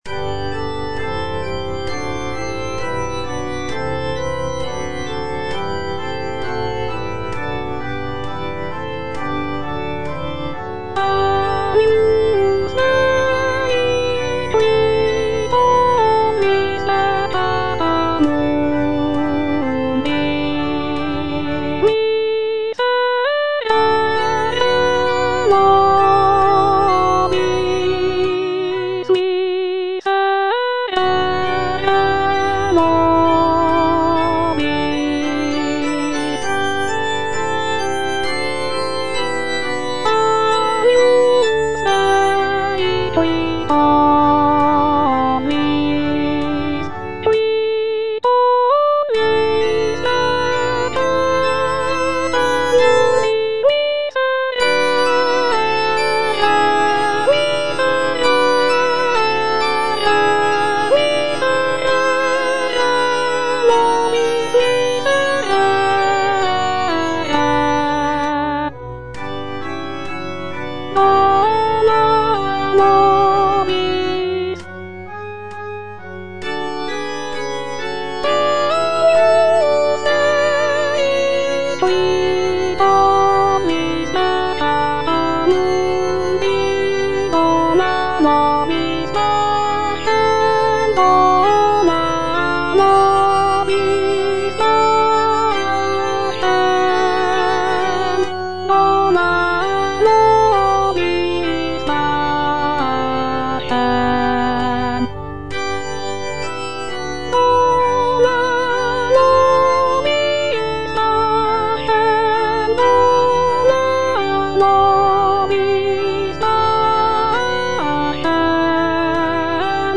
(soprano II) (Voice with metronome) Ads stop